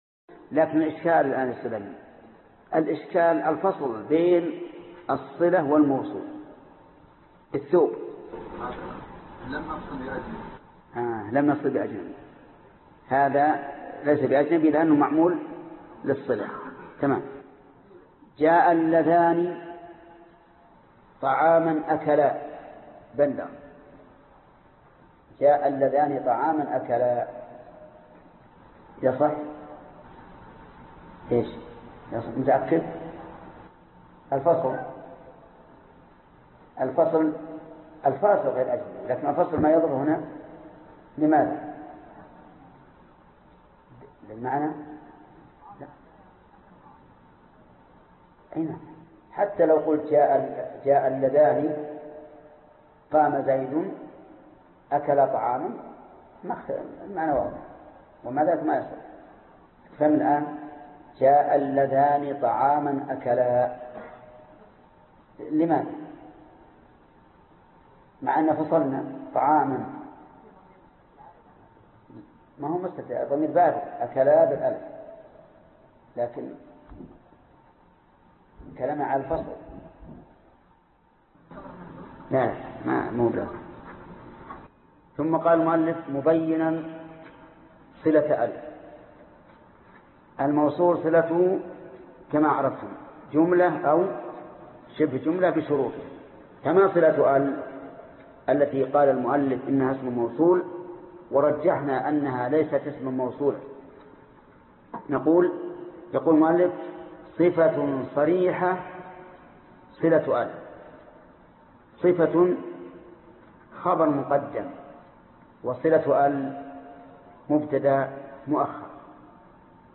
الدرس 73 ( شرح الفية بن مالك ) - فضيلة الشيخ محمد بن صالح العثيمين رحمه الله